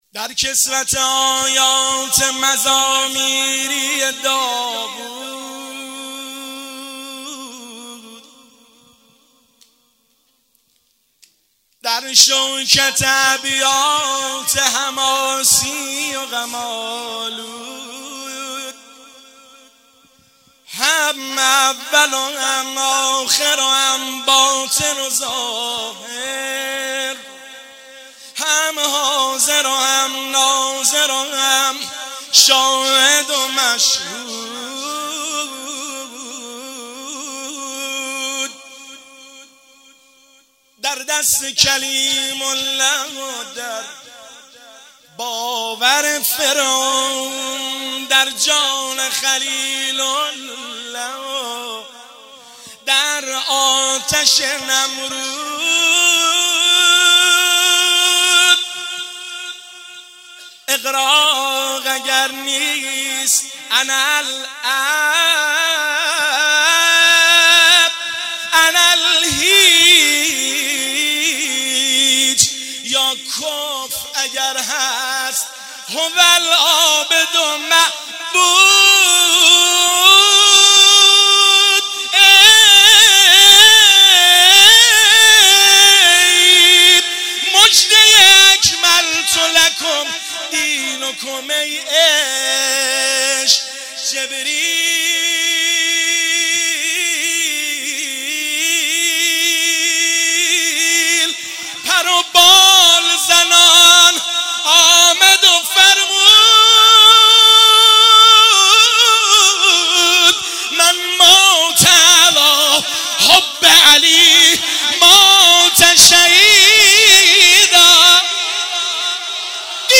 مداحی و نوحه
(شعرخوانی)